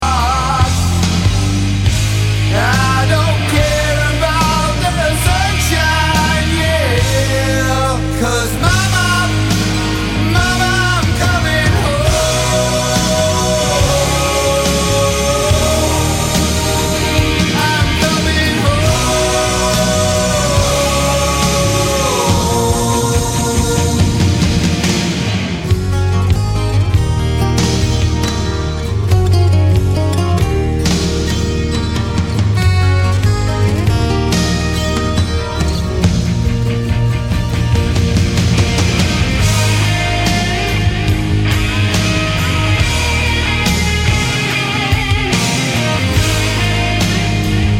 My Favorite Songs with Notable Bass Guitar Performances